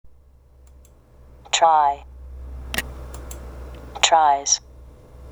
６】yをiに変えてesをつけ「i:z(イーズ)」と発音する動詞